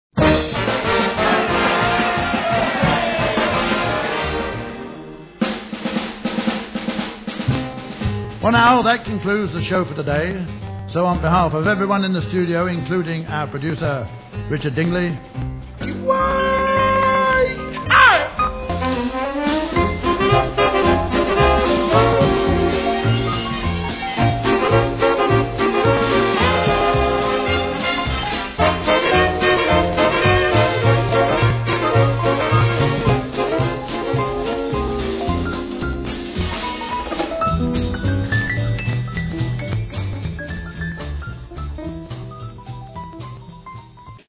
Programme Ending sound clip